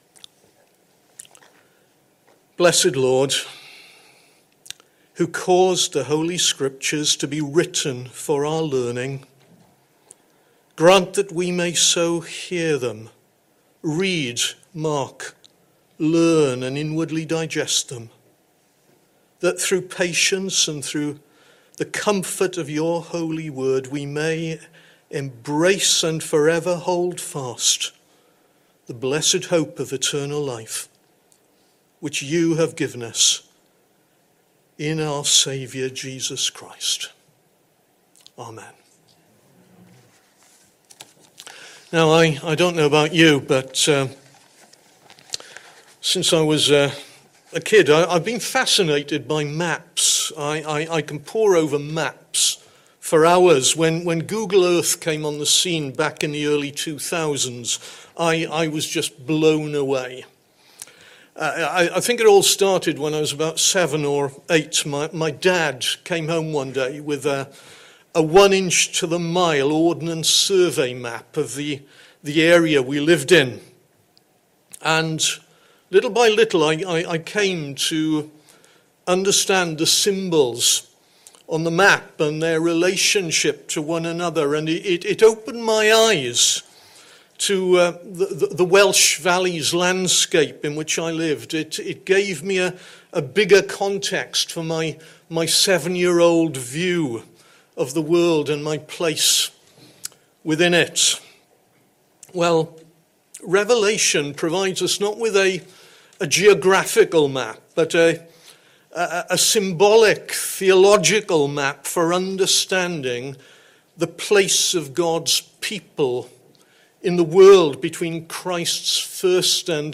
Message: “Genesis 32”
Sunday AM Service Sunday 11th January 2026 Speaker